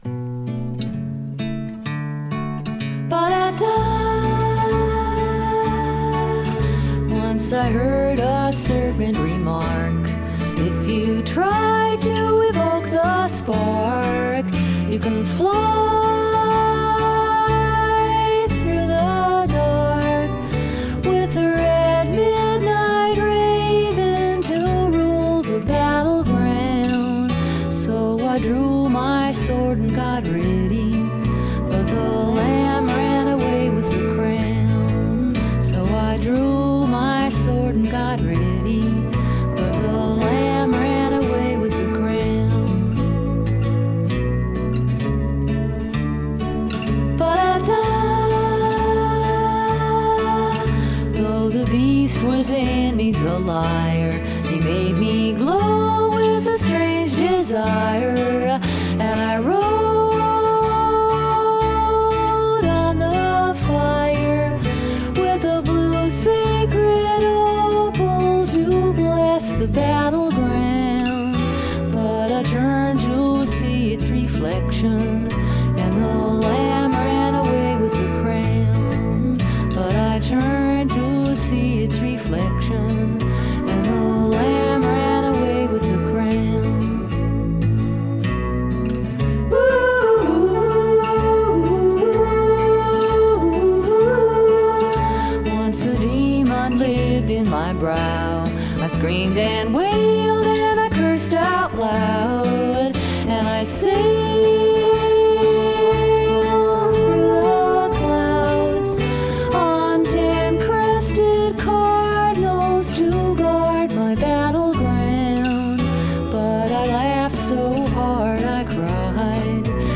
Alternative Experimental International